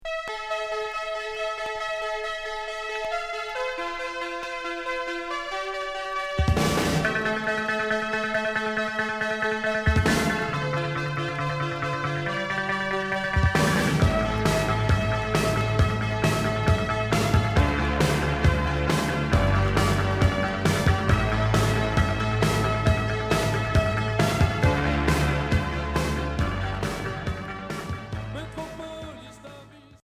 Minimal synth